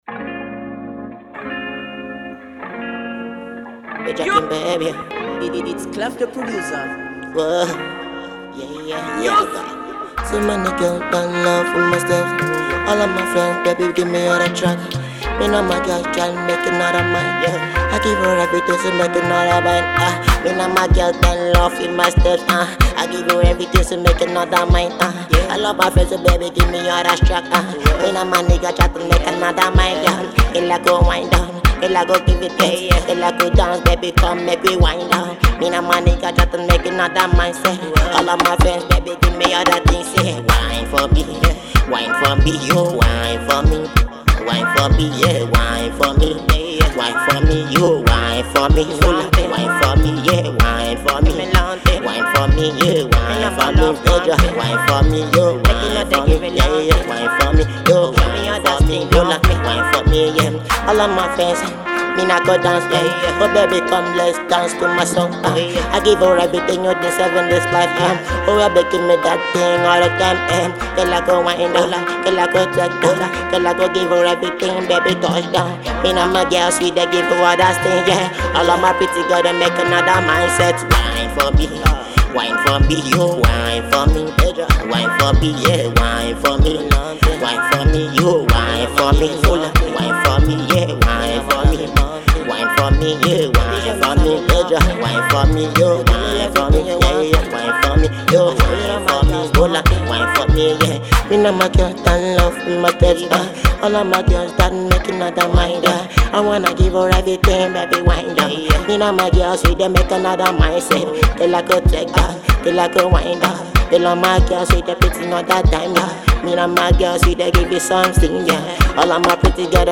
is a radio and club friendly banger